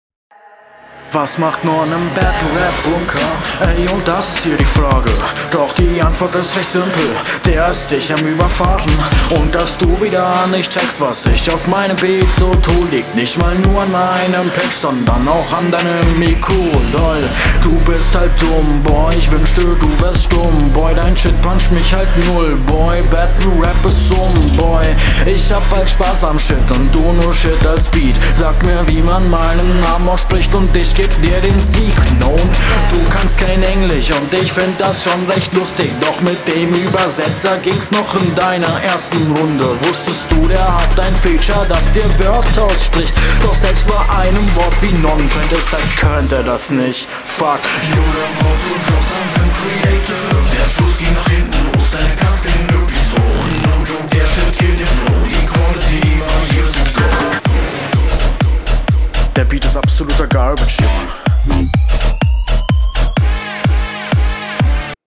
Der Flow ist sehr nice.
kommst wesentlich sicherer auf dem Beat klar . das Gesamtpaket hört sich auch stabiler an.